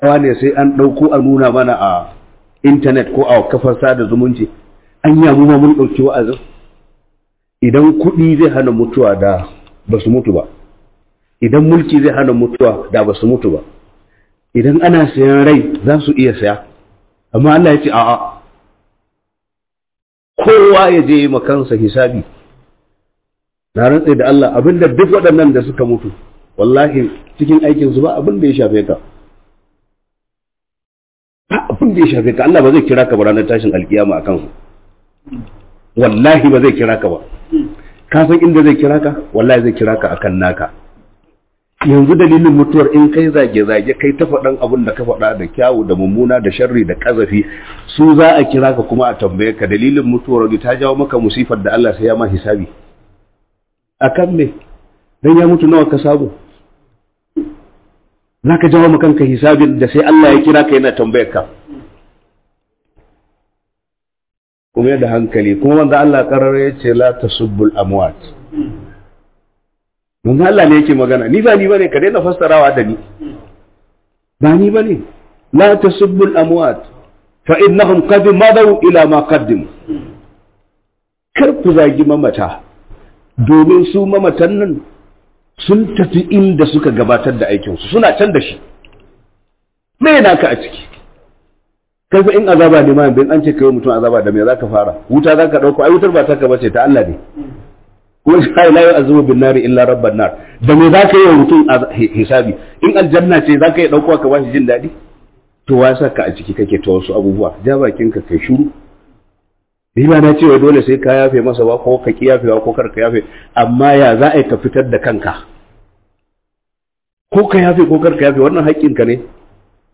MUTUWA WA'AZI CE GA KOWA - HUDUBA